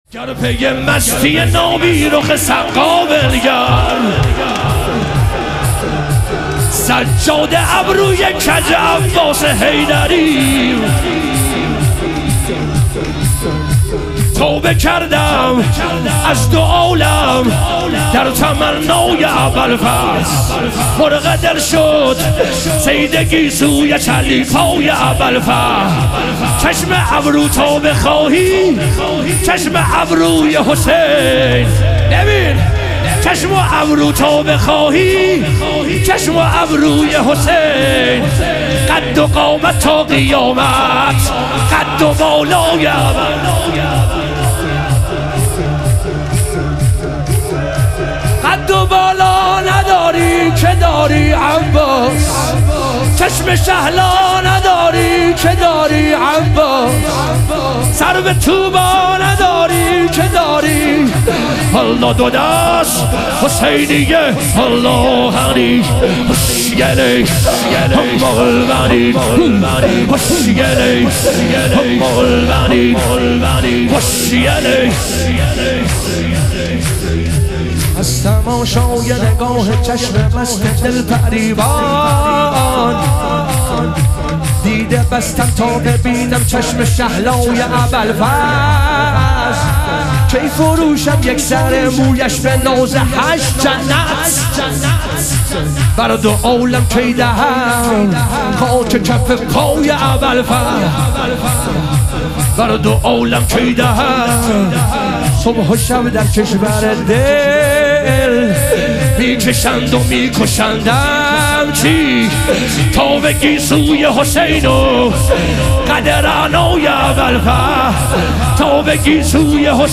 لیالی قدر و شهادت امیرالمومنین علیه السلام - شور